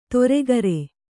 ♪ toregare